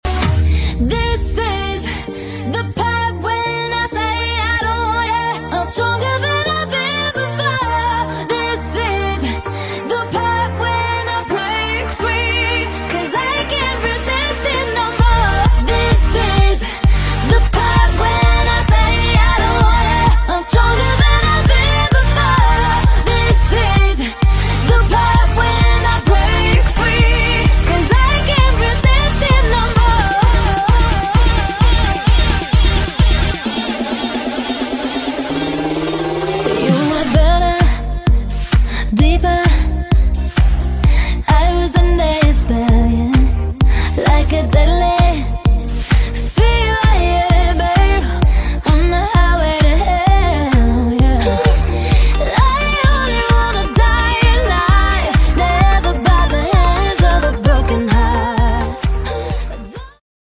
BackingVocals Mix Version